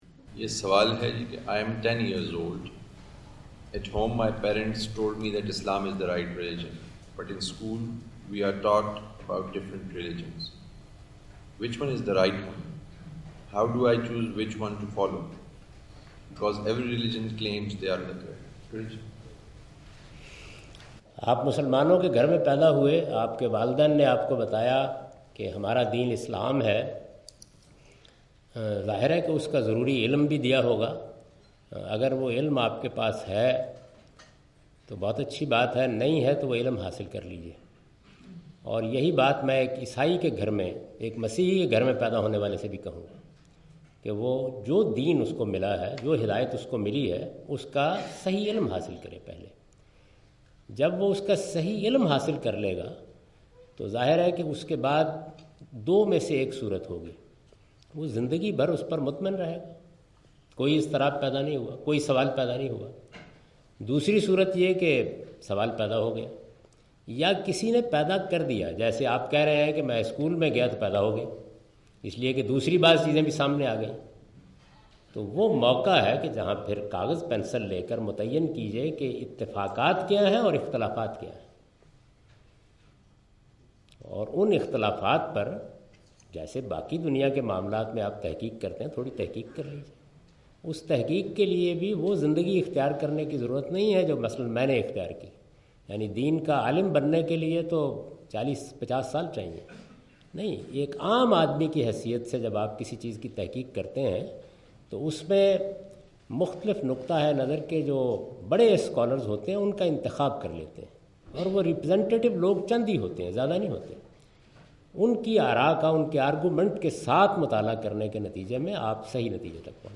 Javed Ahmad Ghamidi answers the question "Which is the true religion?" during his Visit of Brunel University London in March 12, 2016.
جاوید احمد صاحب غامدی اپنے دورہ برطانیہ 2016 کےدوران برونل یونیورسٹی لندن میں "کون سا دین سچا ہے؟" سے متعلق ایک سوال کا جواب دے رہے ہیں۔